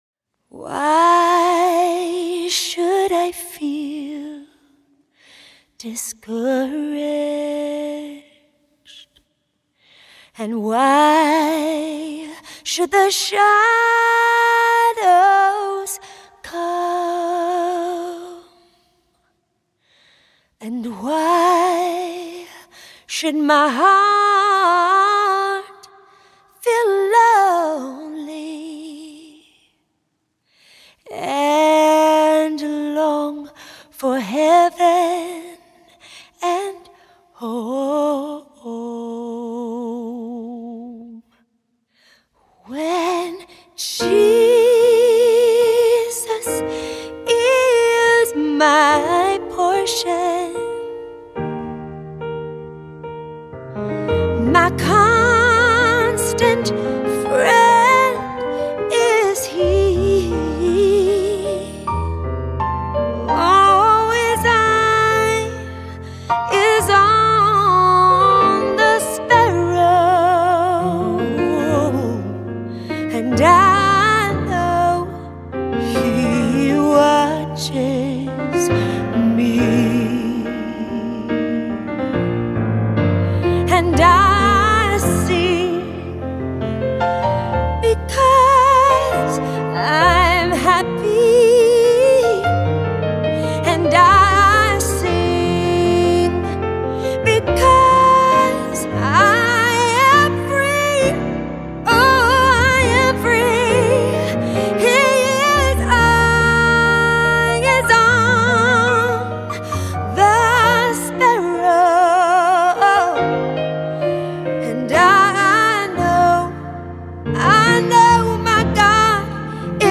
песня
42 просмотра 39 прослушиваний 1 скачиваний BPM: 70